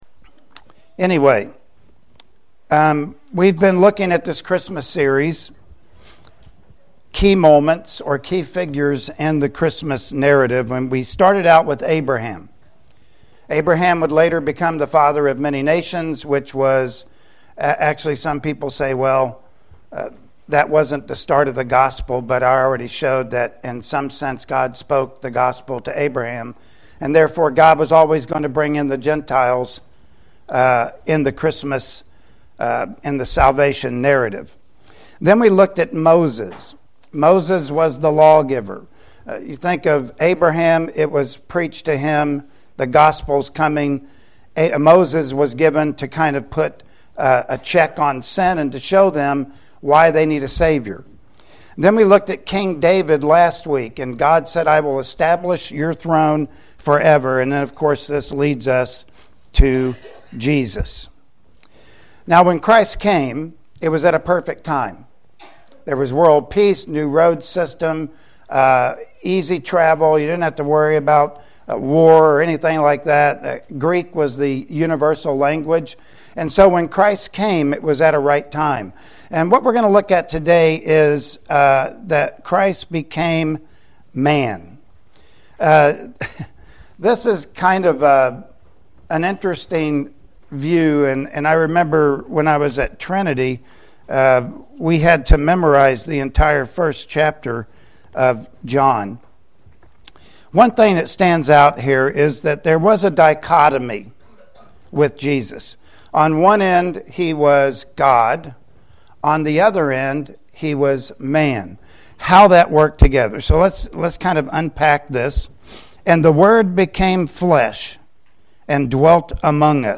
Service Type: Sunday Morning Worship Service Topics: Jesus: Humanity & Divinity